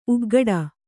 ♪ uggaḍa